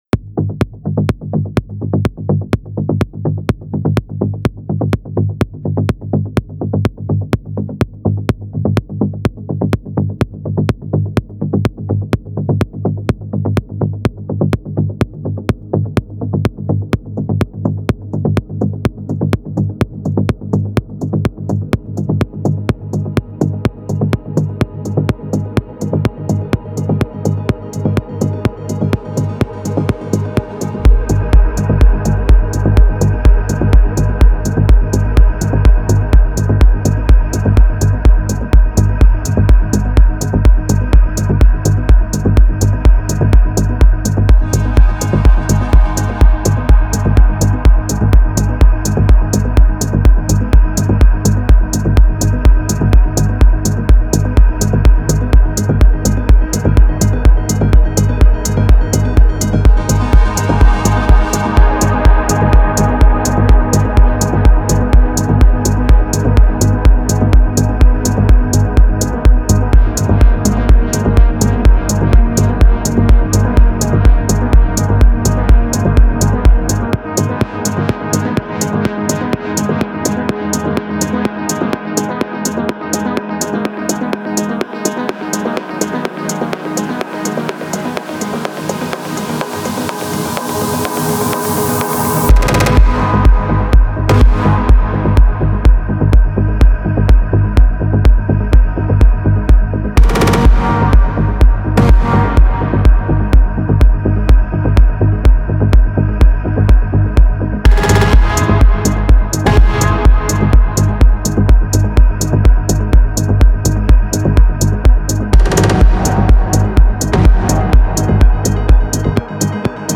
• Жанр: House, Techno